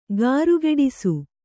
♪ gārugeḍisu